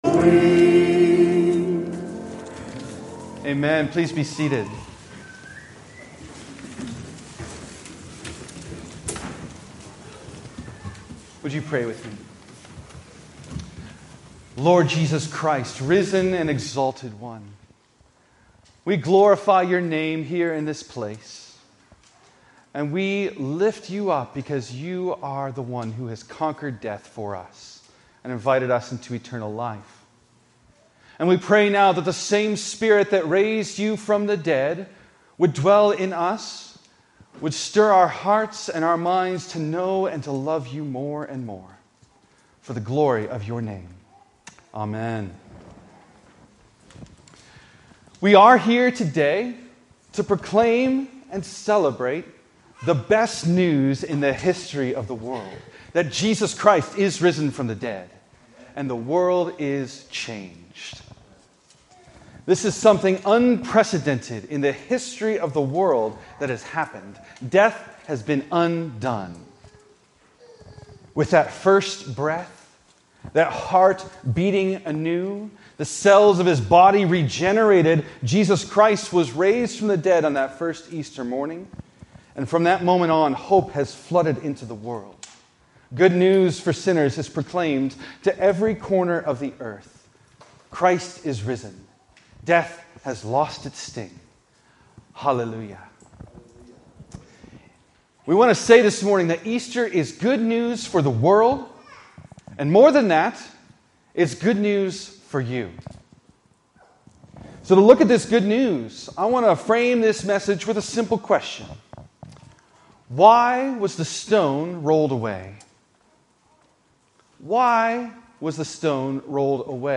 Easter